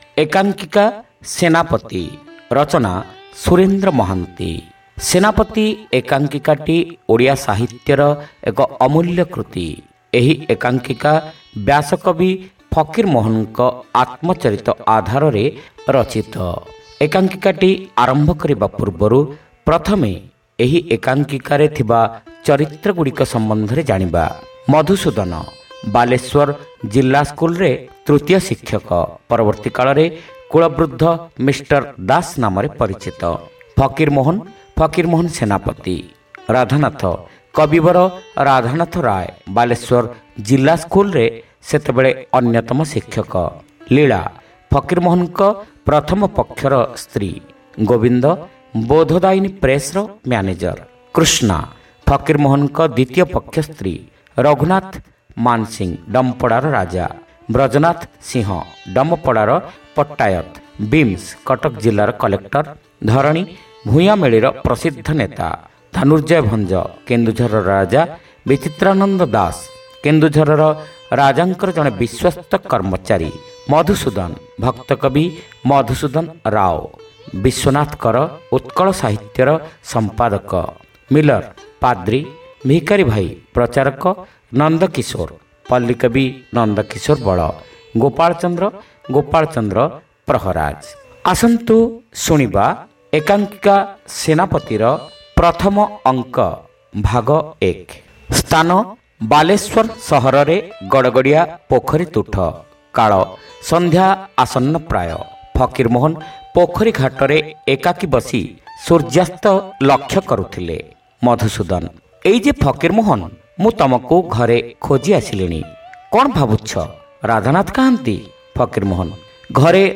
ଶ୍ରାବ୍ୟ ଏକାଙ୍କିକା : ସେନାପତି (ପ୍ରଥମ ଭାଗ)